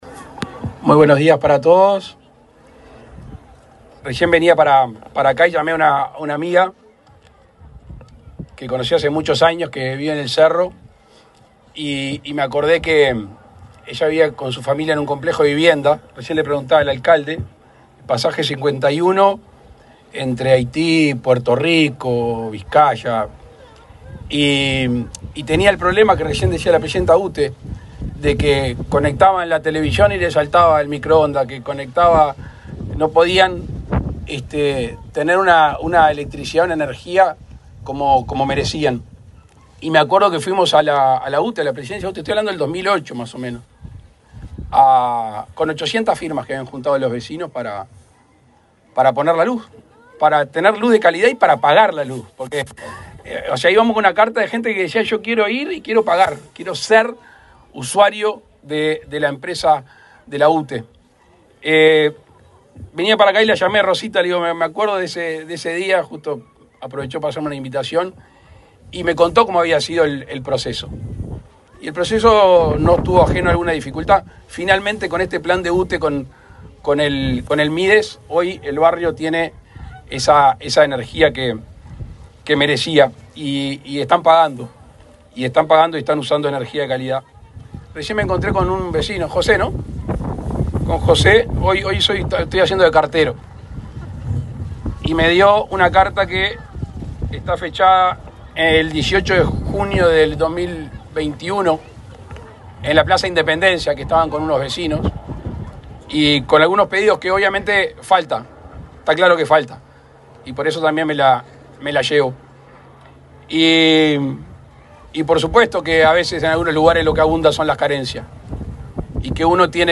Palabras del presidente Luis Lacalle Pou
El presidente de la República, Luis Lacalle Pou, encabezó, este viernes 28 en Montevideo, el acto de inauguración de obras del programa Inclusión